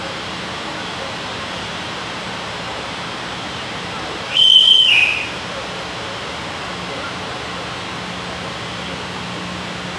Еще один звук свистка
whistle3.wav